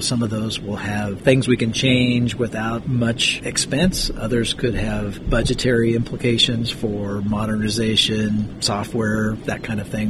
He also says the financial effects of the OEI will vary.